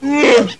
Geräusche
Die Sprache der Wookiees, sie besteht aus Brumm-, Grunz- und Brülllauten.